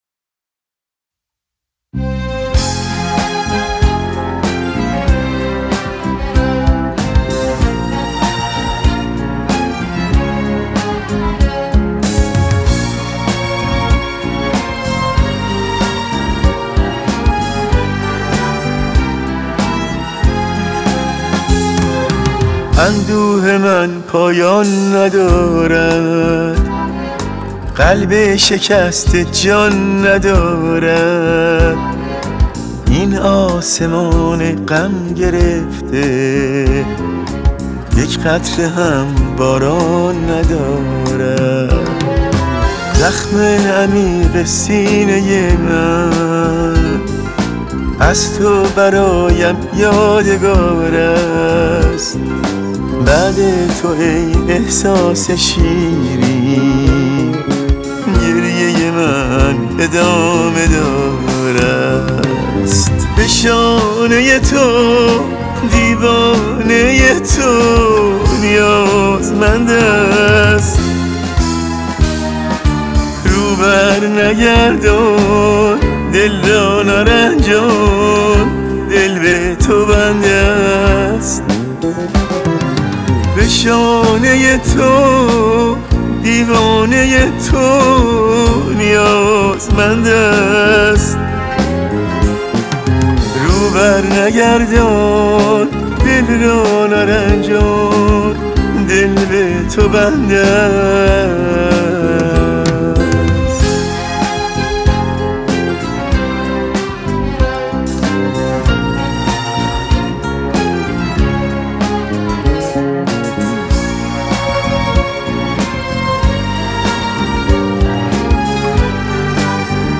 ترانه بسیار زیبا و احساسی